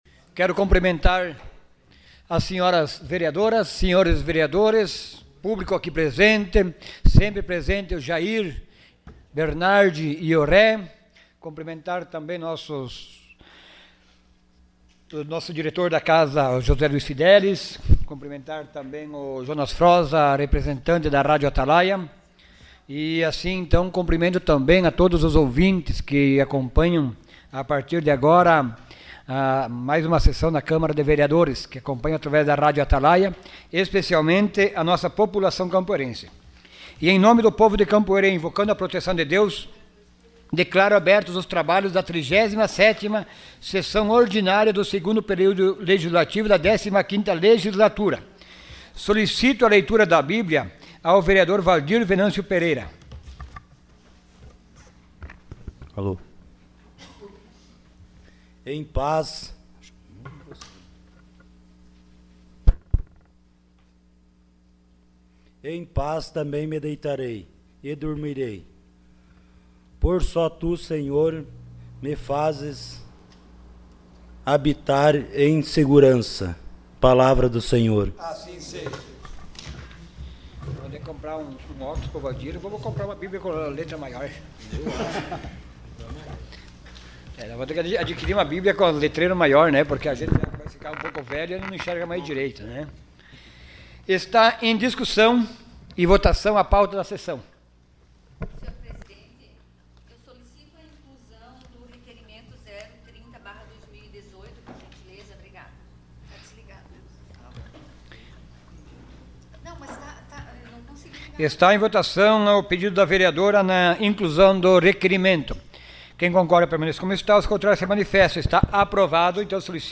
Sessão Ordinária dia 20 de agosto de 2018.